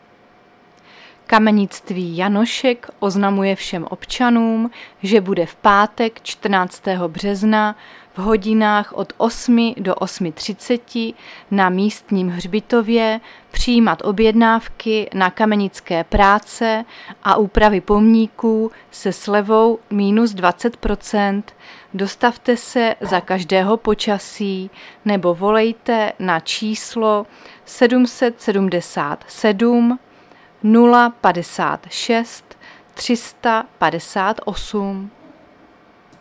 Záznam hlášení místního rozhlasu 12.3.2025
Zařazení: Rozhlas